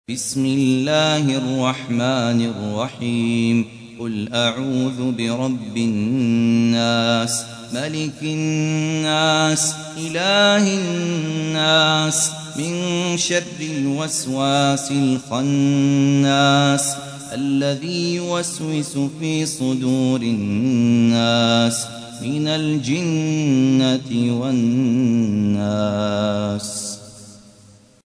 تحميل : 114. سورة الناس / القارئ خالد عبد الكافي / القرآن الكريم / موقع يا حسين